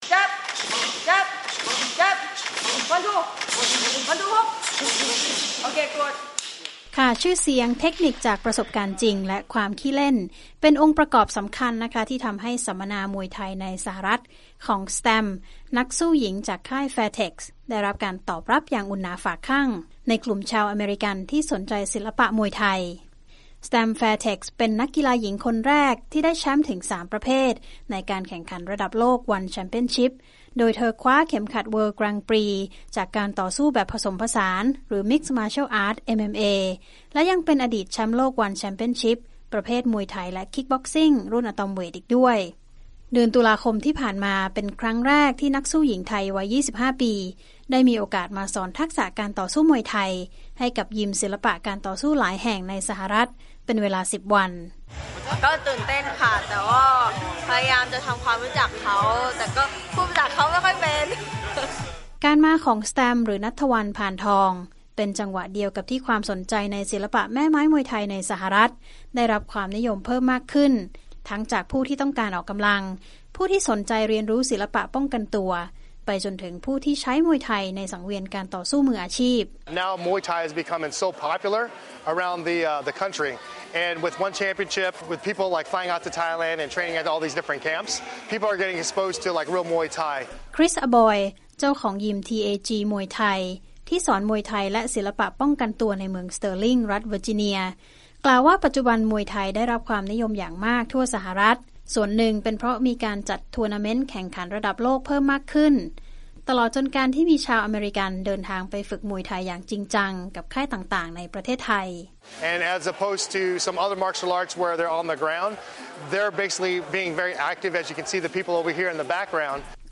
“แสตมป์ แฟร์เท็กซ์” เป็นนักชกหญิงขวัญใจชาวไทยและนักสู้หญิงแถวหน้าระดับโลก เมื่อไม่นานมานี้ แชมป์ วัน (ONE) เวิลด์ กรังด์ปรีซ์ จากการต่อสู้แบบผสมผสาน รุ่นอะตอมเวต ได้มีโอกาสเดินทางมาสหรัฐฯ เป็นเวลา 10 วัน เพื่อสอนทักษะมวยไทยให้กับชาวอเมริกันเป็นครั้งแรก ติดตามได้จากรายงานพิเศษจากวีโอเอไทยตอนที่ 1